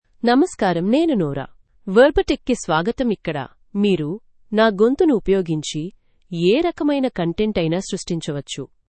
Nora — Female Telugu AI voice
Nora is a female AI voice for Telugu (India).
Voice sample
Listen to Nora's female Telugu voice.
Nora delivers clear pronunciation with authentic India Telugu intonation, making your content sound professionally produced.